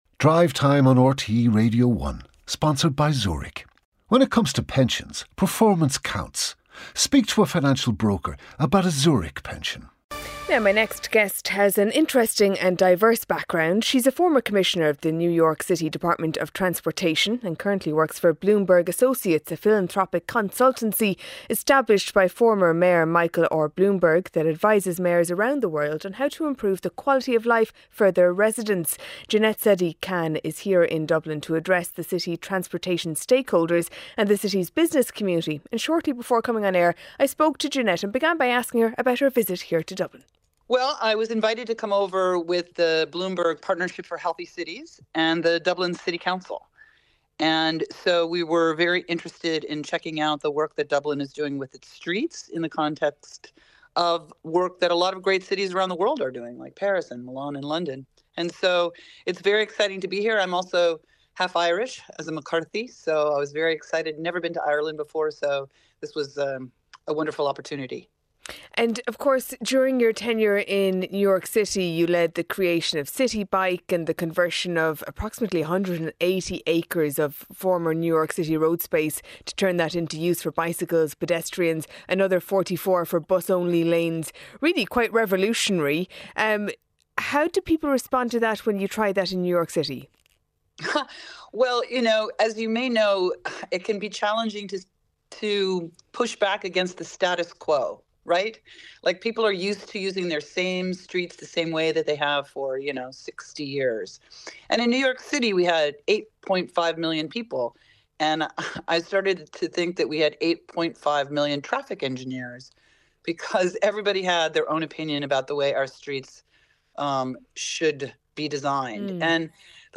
Highlights from the daily news programme with Sarah McInerney and Cormac Ó hEadhra. Featuring all the latest stories, interviews and special reports.